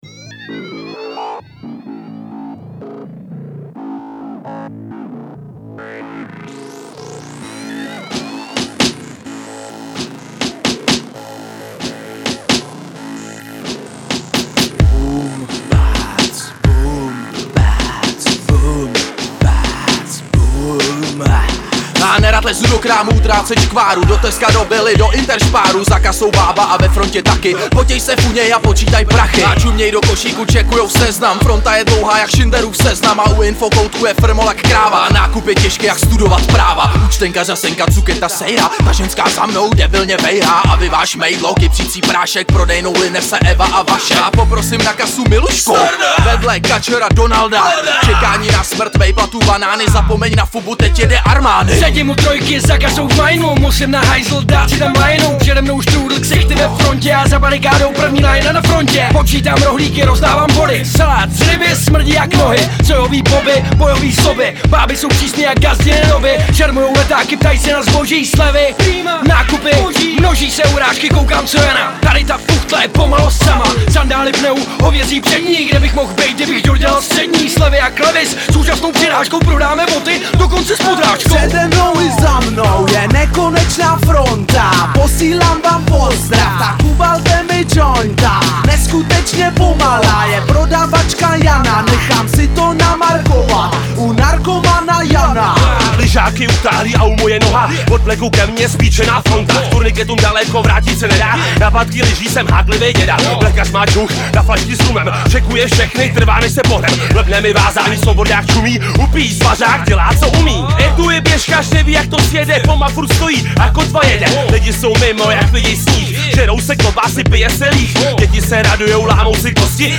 Styl: Hip-Hop